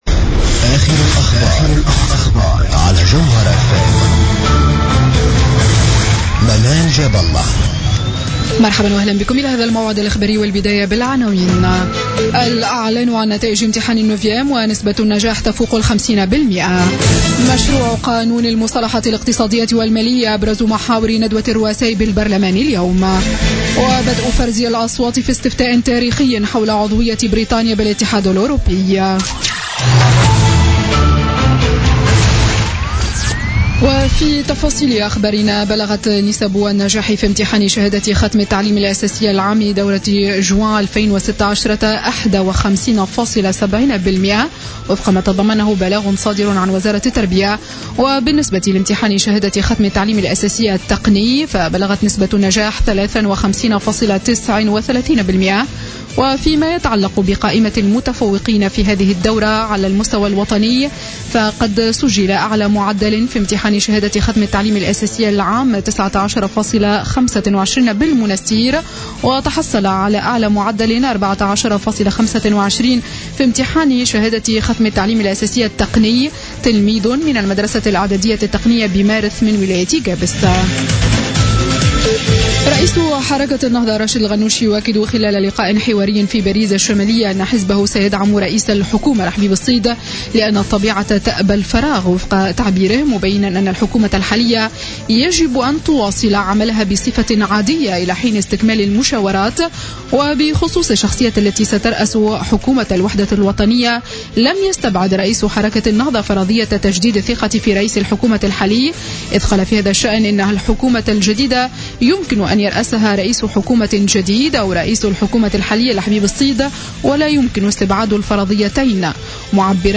نشرة أخبار منتصف الليل ليوم الجمعة 24 جوان 2016